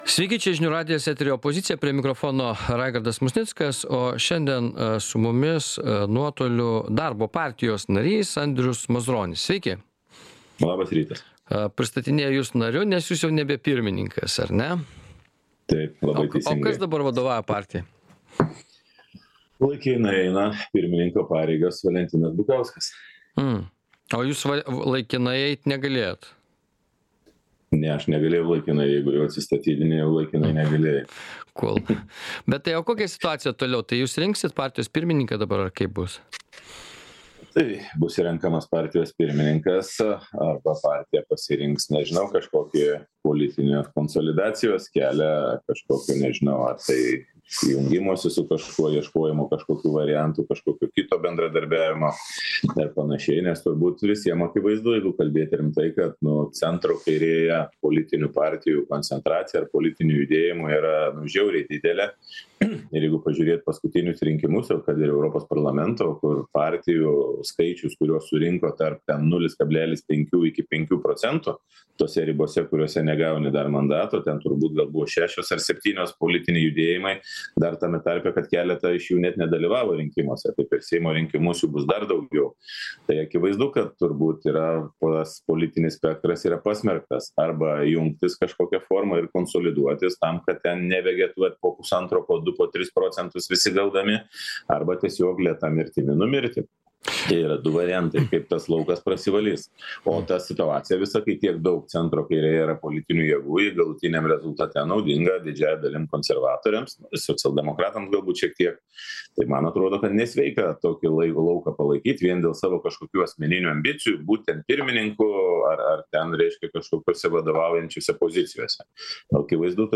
Laidoje dalyvauja Darbo partijos narys Andrius Mazuronis.